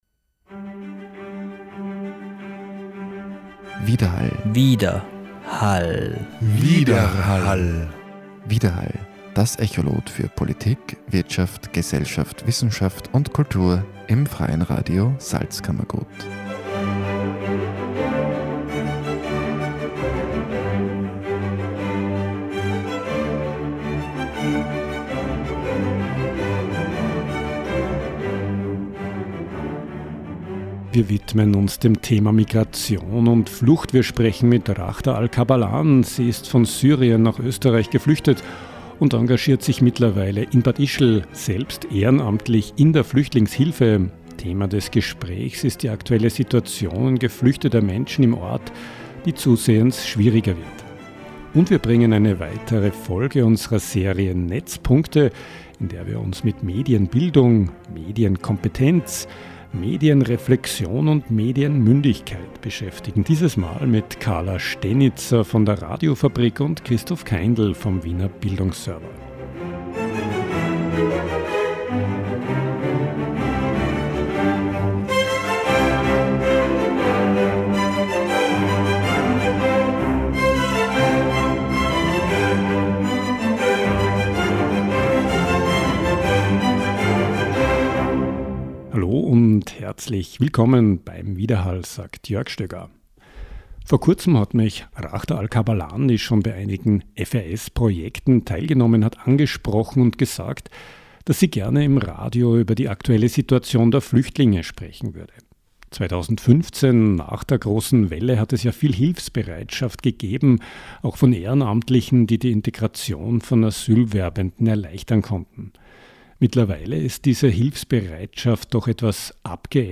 – ein Studiogespräch.